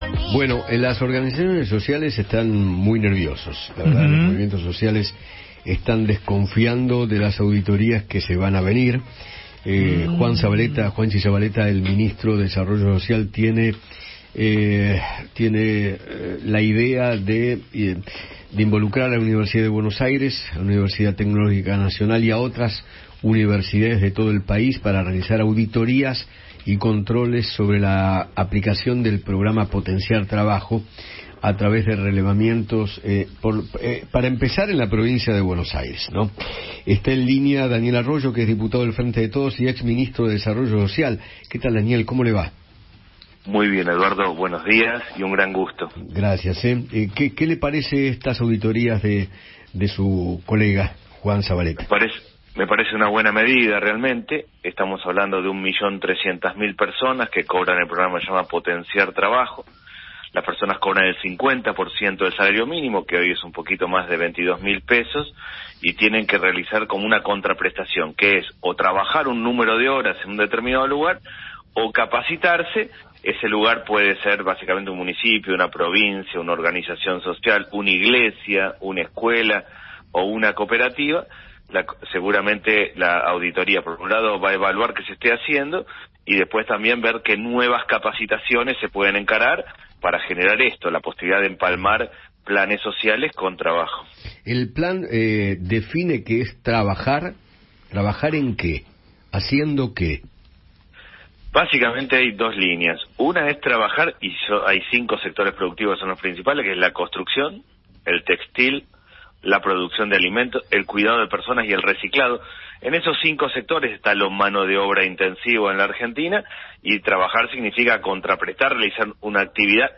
Daniel Arroyo, Diputado Nacional del Frente de Todos, conversó con Eduardo Feinmann acerca de las auditorías de la UBA y de la UTN sobre la aplicación del Potenciar Trabajo que comenzarán la próxima semana y analizó la actualidad de las organizaciones sociales.